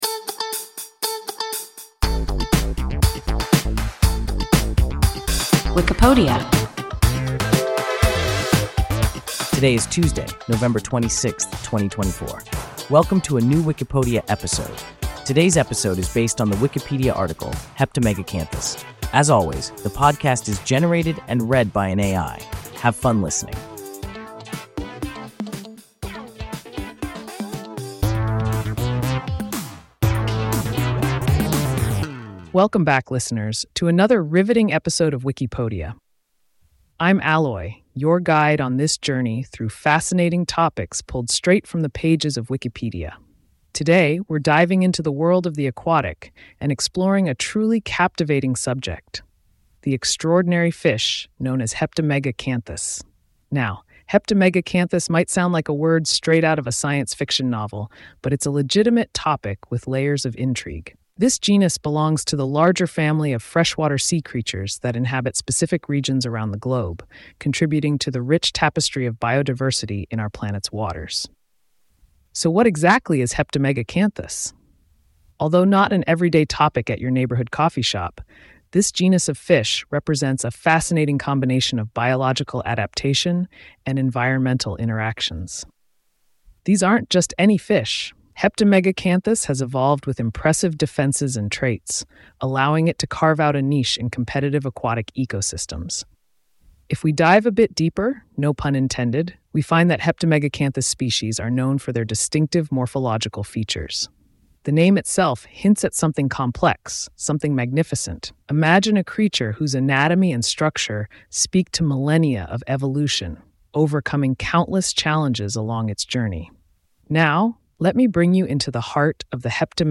Heptamegacanthus – WIKIPODIA – ein KI Podcast